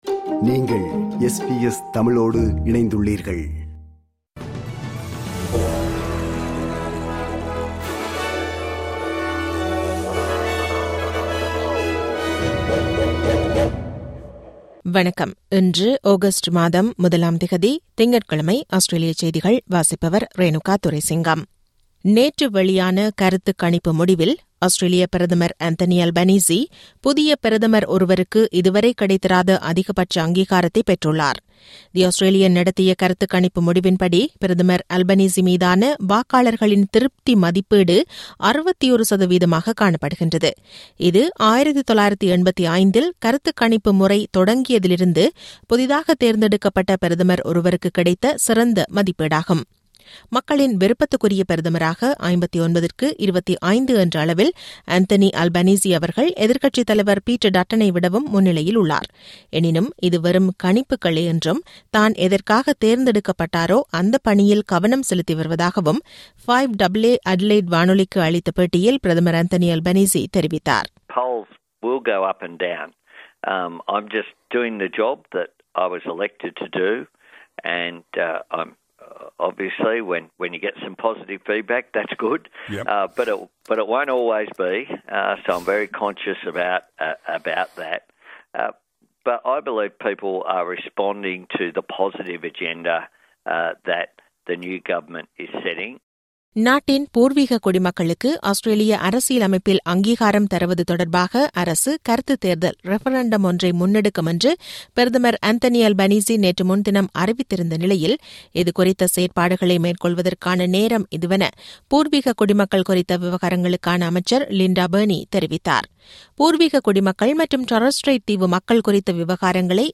Australian news bulletin for Monday 1 Aug 2022.